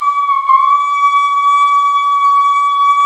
Index of /90_sSampleCDs/Roland LCDP12 Solo Brass/BRS_Cup Mute Tpt/BRS_Cup Ambient